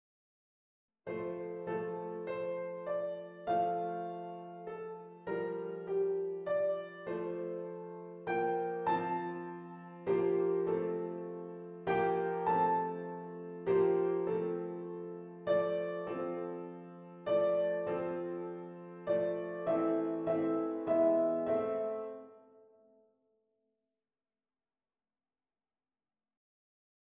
Schubert, sonate in a-klein D 845, derde deel, begin van het Trio (maat 128-150): Octaafverdubbeling.
Uitgevoerd door Maria João Pires.